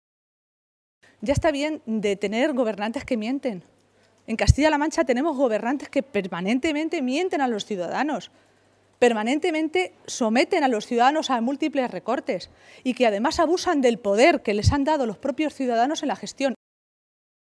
Maestre se pronunciaba de esta manera esta tarde, en una comparecencia ante los medios de comunicación minutos antes de que se reuniera en Toledo la dirección regional socialista.